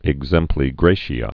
(ĭg-zĕmplē grāshē-ə, ĕk-sĕmplē grätē)